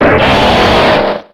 Cri de Jungko dans Pokémon X et Y.